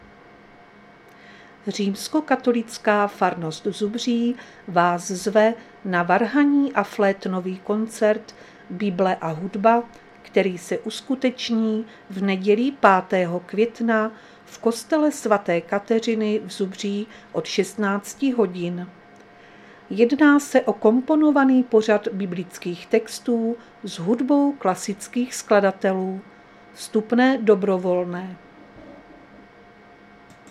Záznam hlášení místního rozhlasu 30.4.2024
Zařazení: Rozhlas